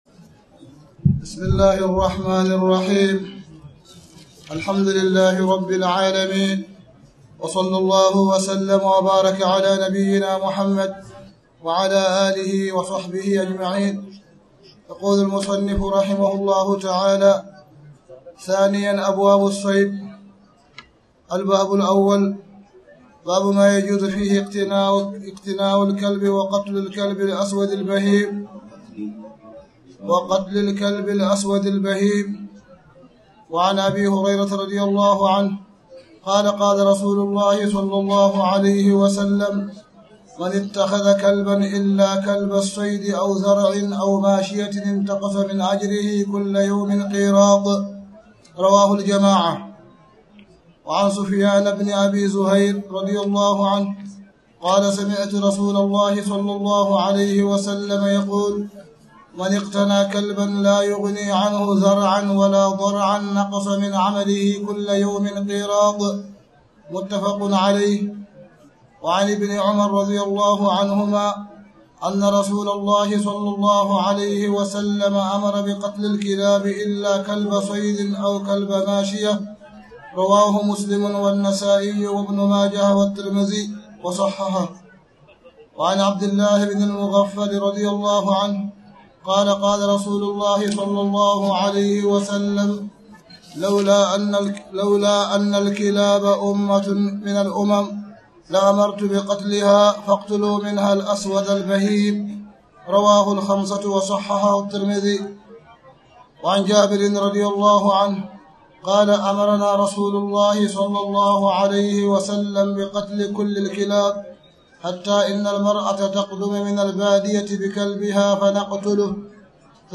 تاريخ النشر ١ ذو الحجة ١٤٣٨ هـ المكان: المسجد الحرام الشيخ: معالي الشيخ أ.د. صالح بن عبدالله بن حميد معالي الشيخ أ.د. صالح بن عبدالله بن حميد باب ما يجوز فيه إقتناء الكلب وقتل الكلب